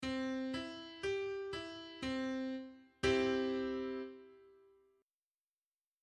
They feature a whole or complete sound, as opposed to Minor chords which tend to sound morose and dramatic.
piano chord chart C major
C_Major.mp3